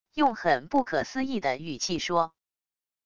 用很不可思议的语气说wav音频